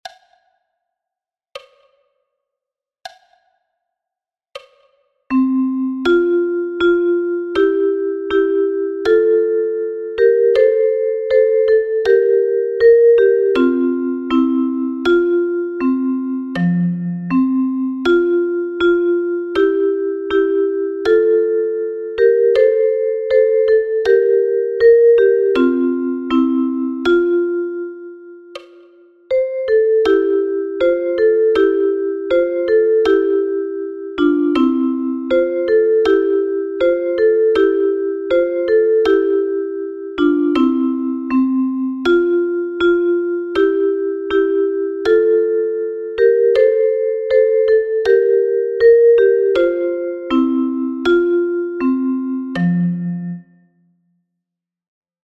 notiert als Duette für Sopranblockflöte und Altblockflöte.